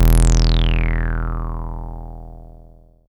77.10 BASS.wav